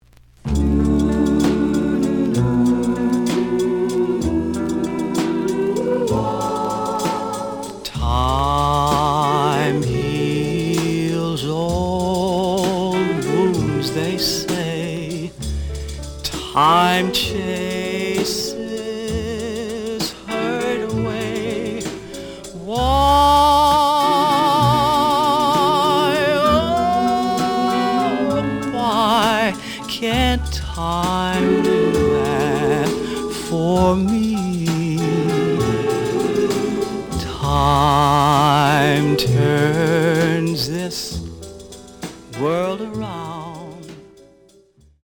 試聴は実際のレコードから録音しています。
●Genre: Rhythm And Blues / Rock 'n' Roll
EX-, VG+ → 傷、ノイズが多少あるが、おおむね良い。